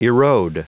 Prononciation du mot erode en anglais (fichier audio)
Prononciation du mot : erode